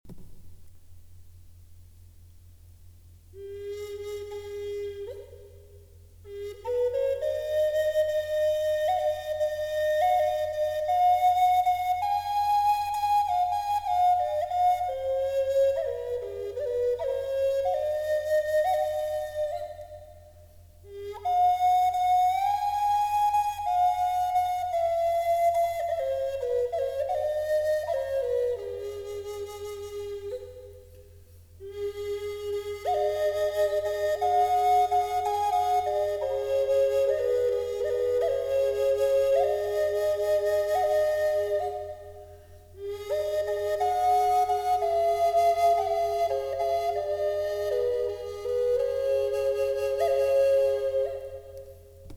Triple Flûte amérindienne en Chêne et Hêtre • Sol#
Accordée en 432 Hz sur la gamme harmonique mineure de Sol#, elle offre une sonorité envoûtante, à la fois mystique et introspective.
Son jeu en triple voix permet des harmoniques riches et vibrantes, idéales pour la méditation, les voyages sonores ou la création intuitive.
• Note principale : Sol#, accordée en 432 Hz
• Gamme : harmonique mineure
triple-flute-sol-diese.mp3